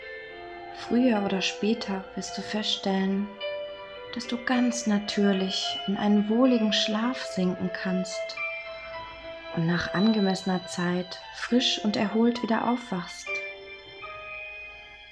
Mit unserer Hypnose-MP3 werden Sie dabei unterstützt, schneller und natürlicher einzuschlafen.